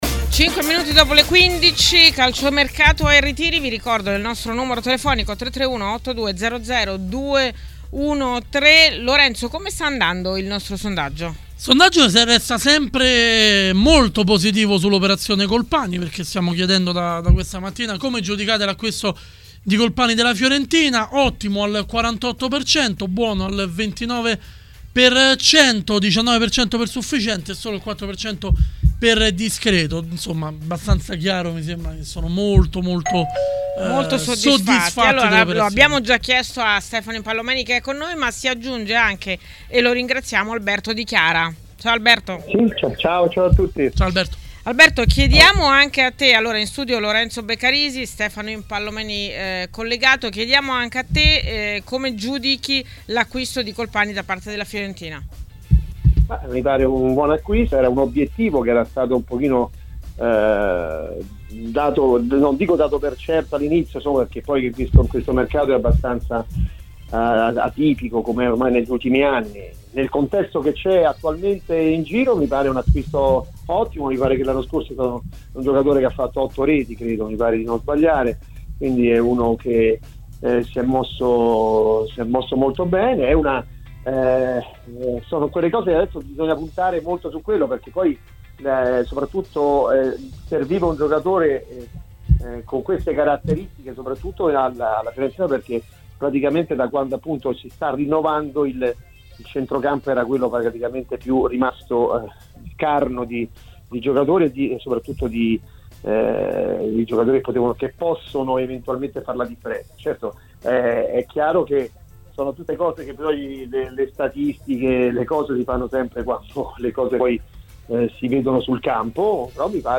Nel corso di 'Calciomercato e Ritiri', trasmissione del pomeriggio di Tmw Radio, è intervenuto l'ex calciatore Alberto Di Chiara.